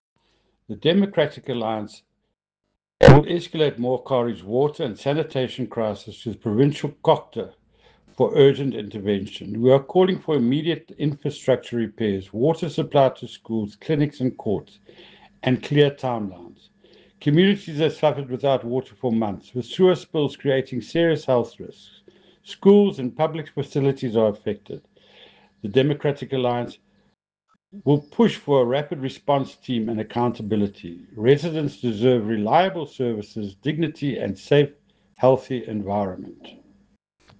English soundbite by Cllr Ian Riddle,